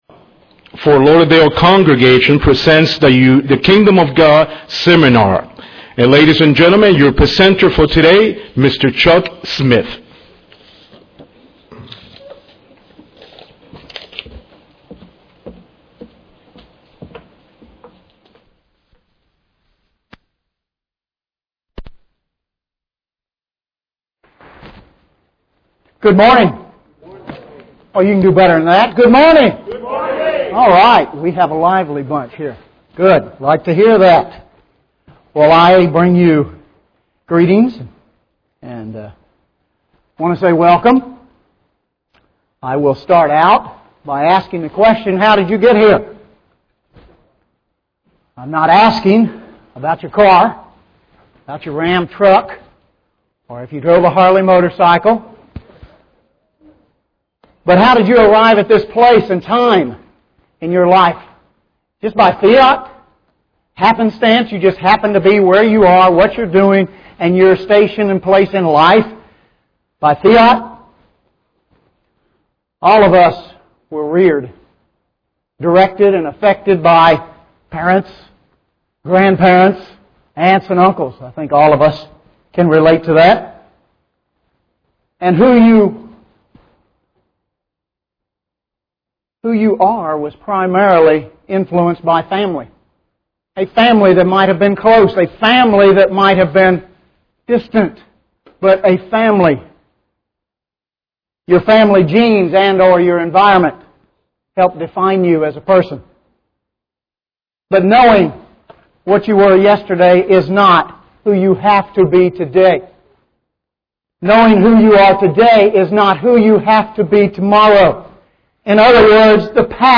Mankind has always needed help, mentally, physically, spiritually. This Kingdom of God seminar discusses Jesus Christ's first coming.